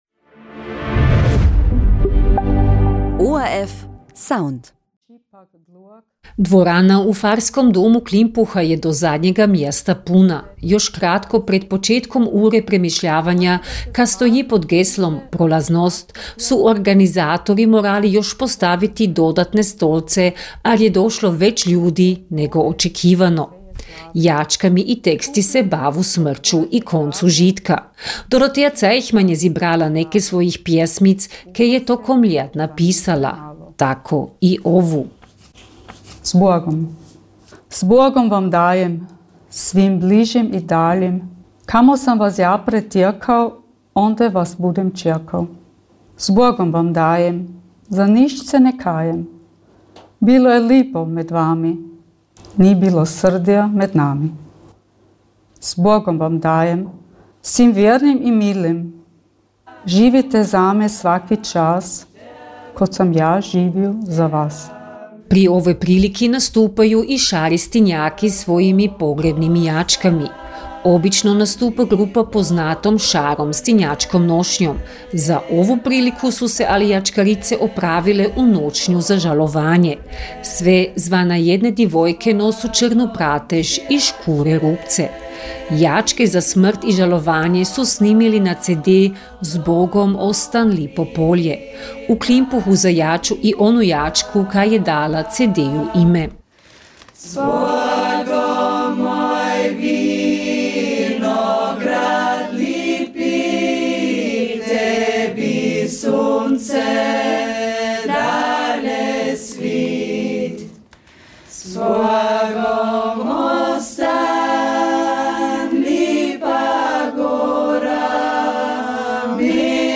Čitanje i jačke u Klimpuhu na temu „Prolaznost“
U Klimpuhu su na Mrtvih god – kada se spominja svih pokojnih pozvali na uru čitanjem i muzikom o koncu žitka i smrti.